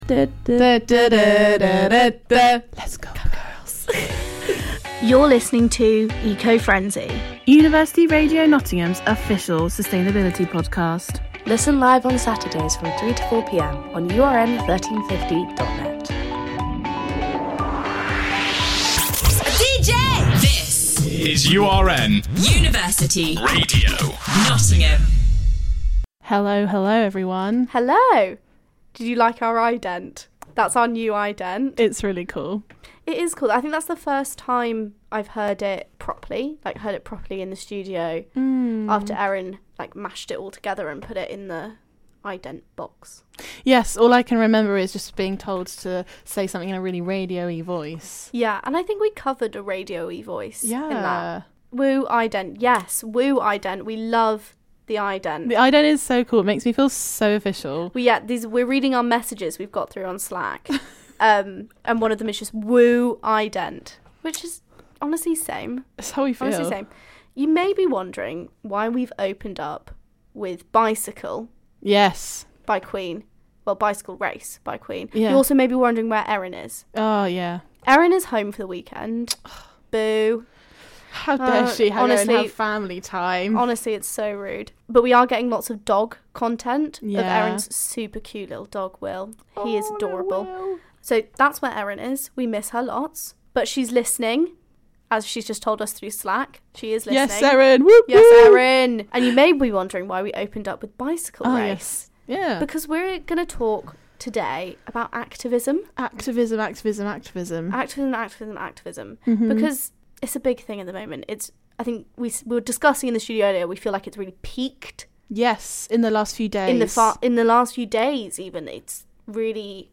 ~ Originally broadcast live on University Radio Nottingham on Saturday 12th November 2022